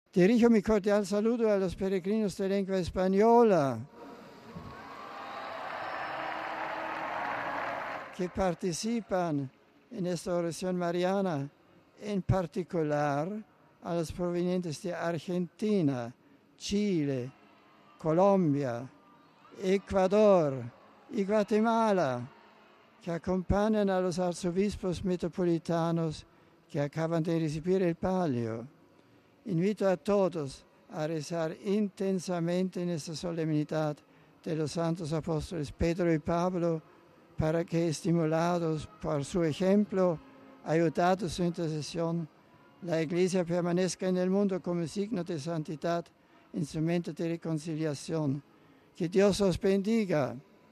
En su saludo a los peregrinos de lengua española, en la solemnidad de los santos Pedro y Pablo, el Papa Benedicto invitó a rezar intensamente, para que estimulados por el ejemplo y la intercesión de estos santos, la Iglesia permanezca signo de Santidad e instrumento de reconciliación.
Palabras del Papa: RealAudio